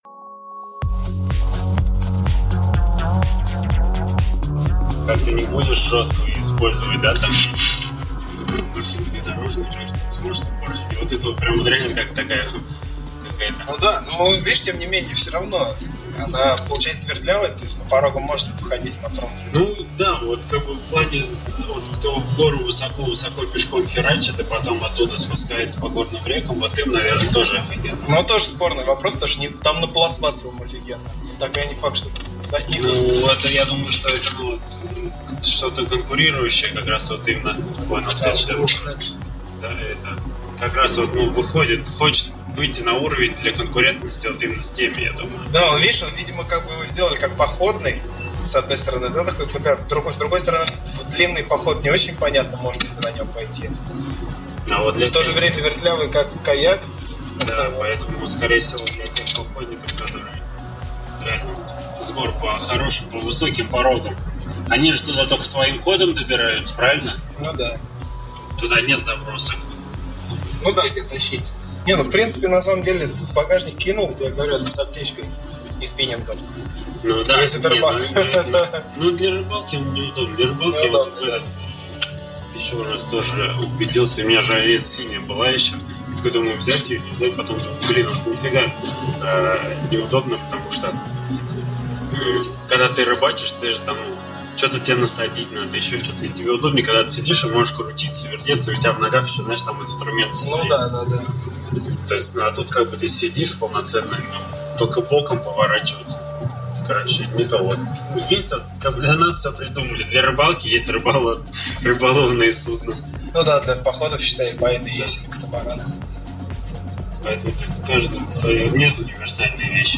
Жанр: Talks.